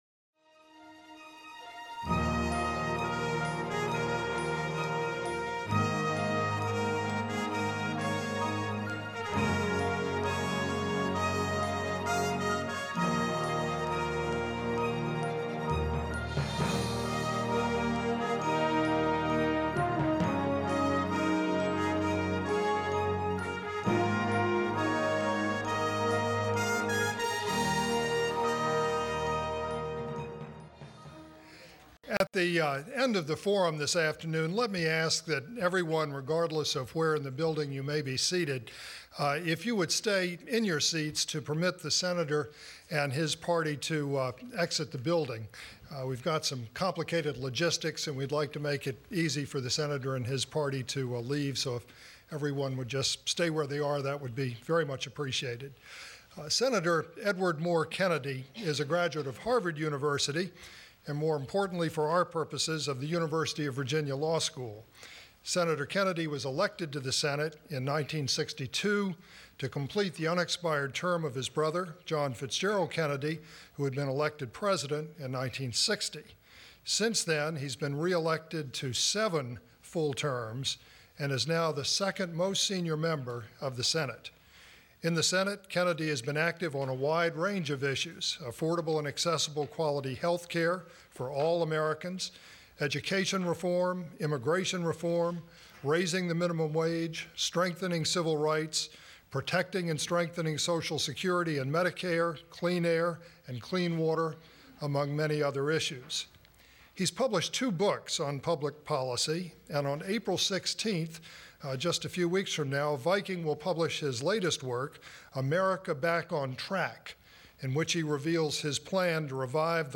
Sen. Edward M. Kennedy, a graduate of the University of Virginia Law School, and the subject of a major Miller Center Oral History project, reflects on his years in public service at a special Forum.